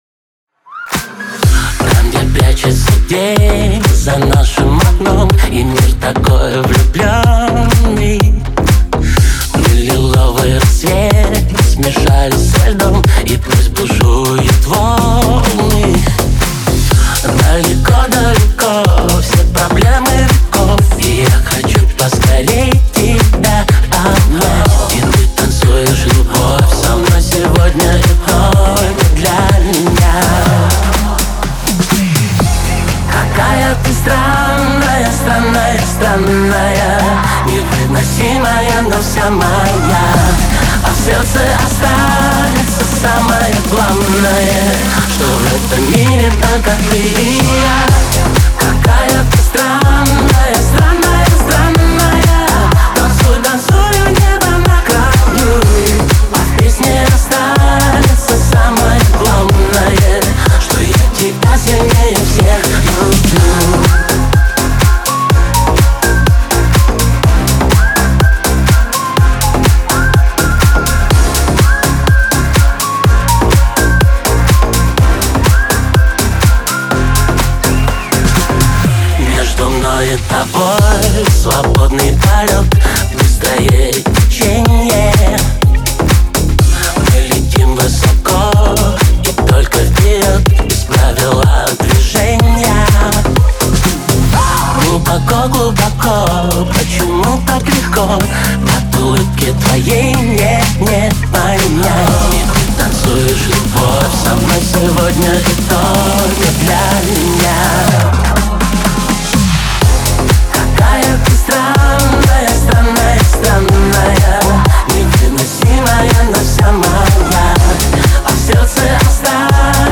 яркая поп-композиция